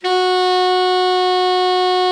Index of /90_sSampleCDs/Giga Samples Collection/Sax/ALTO SAX